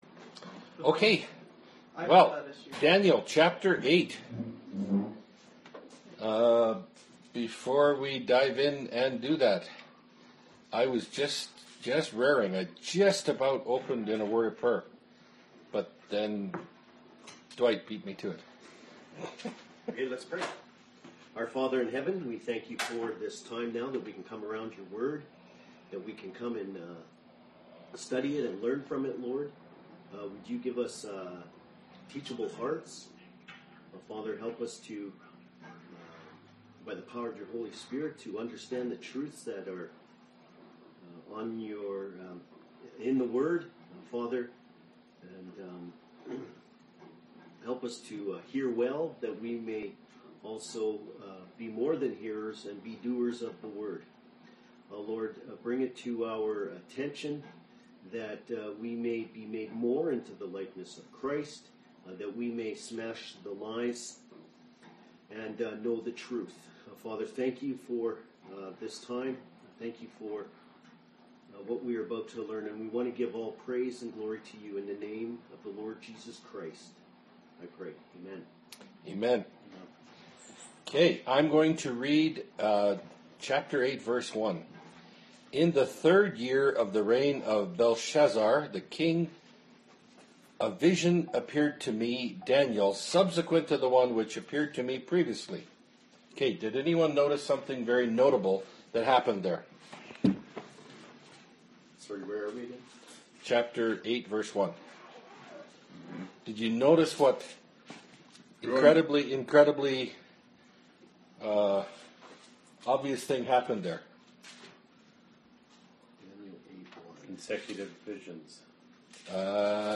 Bible Study – Daniel 8 – Part 1 of 2 (2017)
Category: Bible Studies